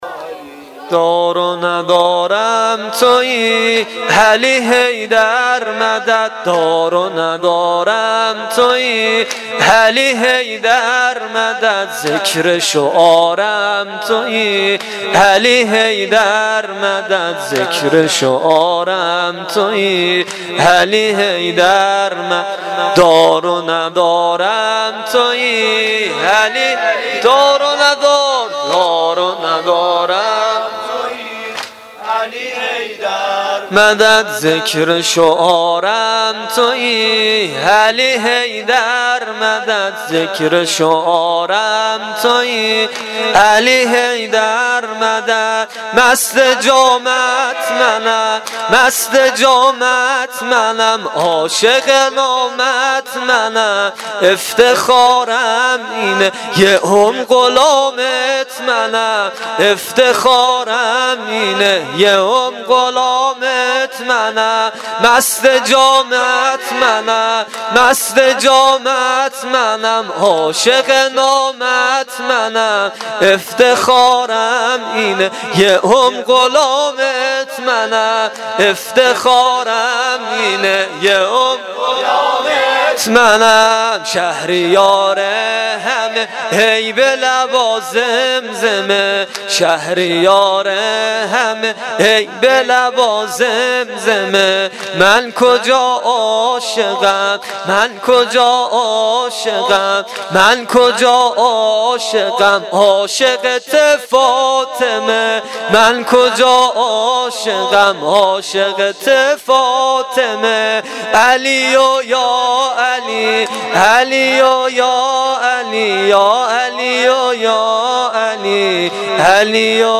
واحد تند شب 21 ماه رمضان